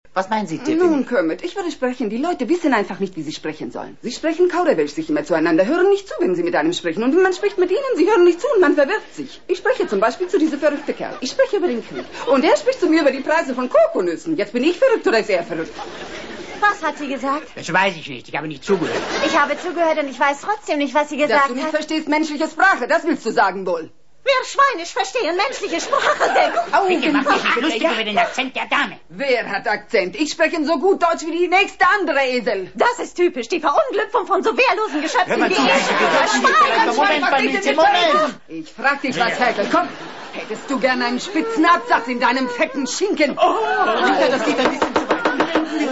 * Dies ist übrigens der falsch besetzte Miss Piggy Sketch - klingt wie Gitarristin Janice.